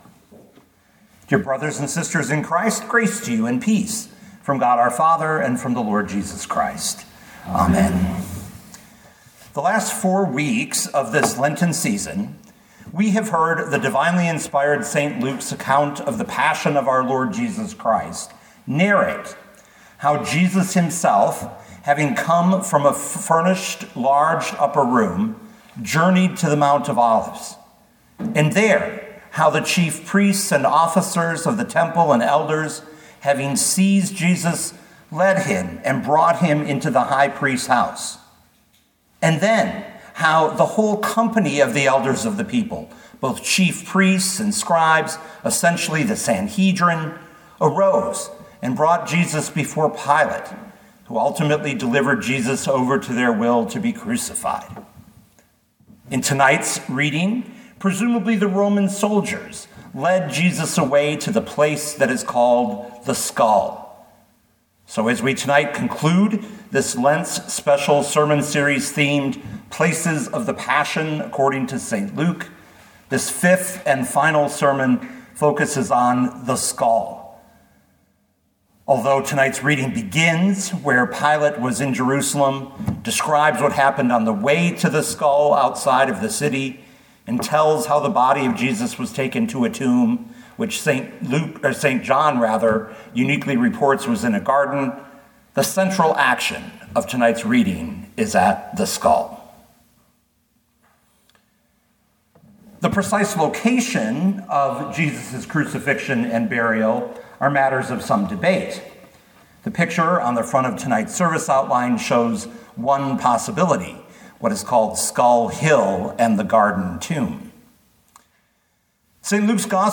2025 Luke 23:26-56 Listen to the sermon with the player below, or, download the audio.